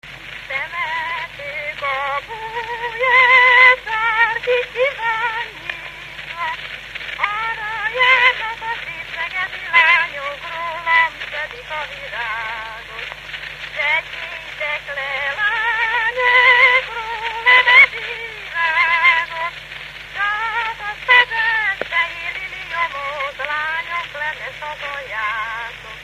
Alföld - Csongrád vm. - Szeged
Gyűjtő: Lajtha László
Stílus: 9. Emelkedő nagyambitusú dallamok
Kadencia: 2 (5) 3 1